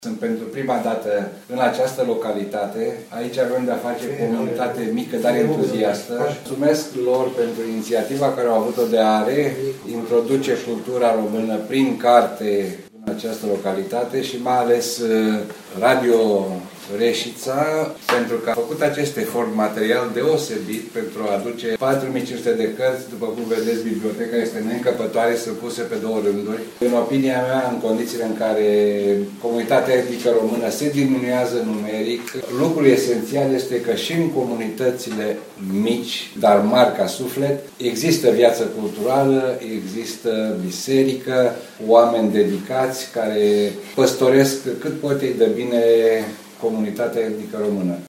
Consulul României la Vârşeţ, excelenţa sa Gheorghe Dinu, a declarat că este prezent pentru prima dată în Mramorak și se bucură că Radio Reșița a făcut aceste efort de a aduce peste patru mii de volume pentru comunitatea românească.